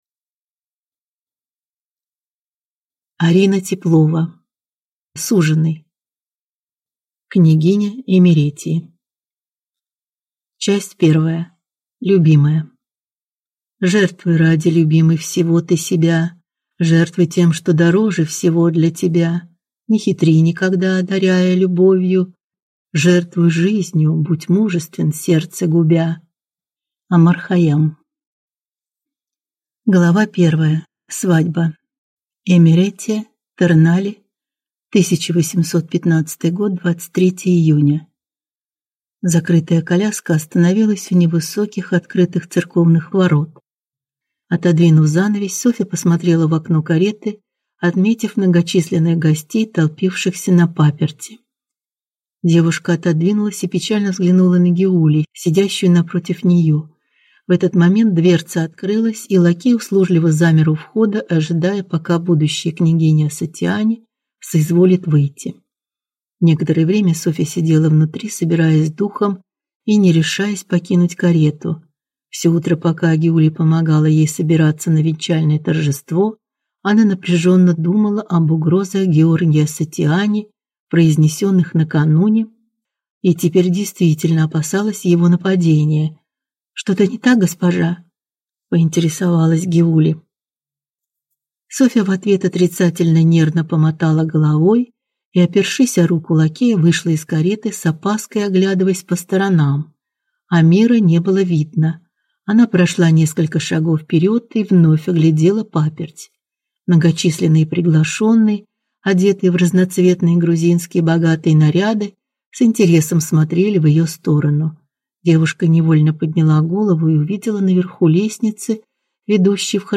Аудиокнига Суженый. Княгиня Имеретии | Библиотека аудиокниг
Прослушать и бесплатно скачать фрагмент аудиокниги